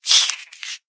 kill.ogg